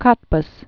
(kŏtbəs, kôtbs)